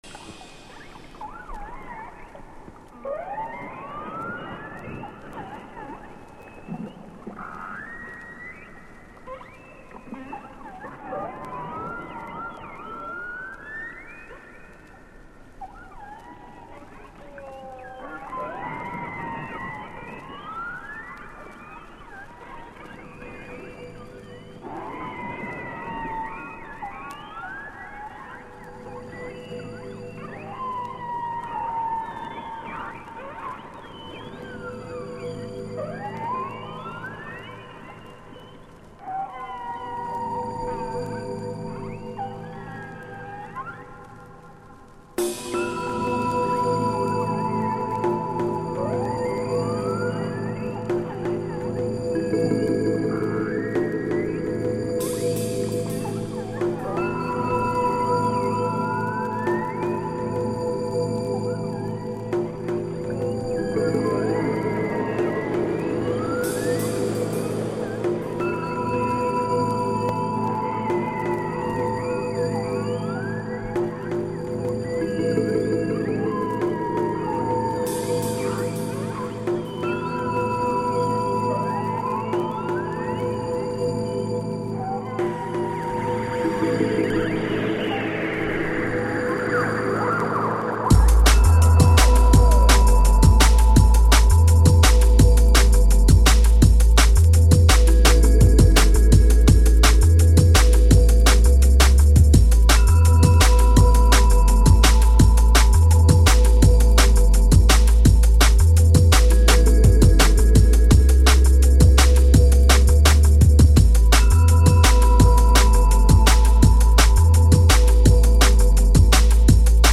Atmospheric, Intelligent Drum & Bass